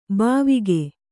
♪ bāvige